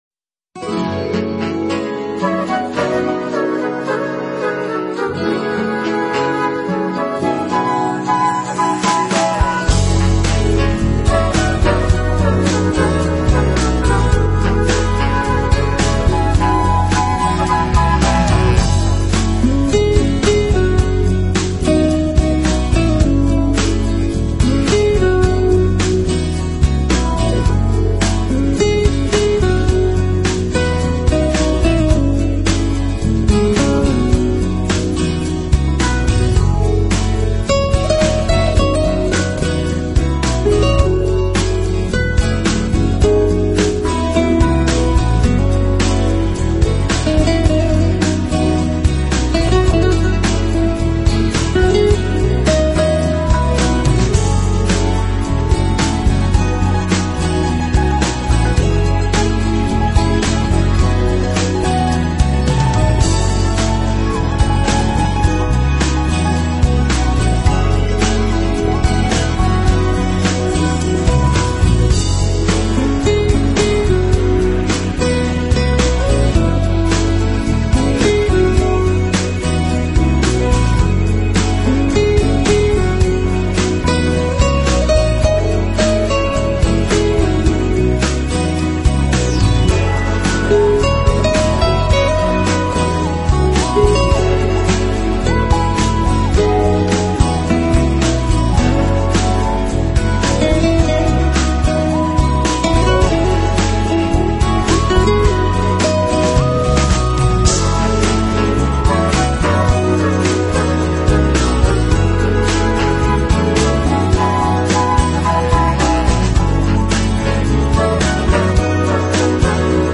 音乐类型：Jazz 爵士
音乐风格：Contemporary，Instrumental，Smooth Jazz，New Age
大量长笛和SAX的成分很容易使人忽略掉吉他的存在， 乐团是想要把这首曲子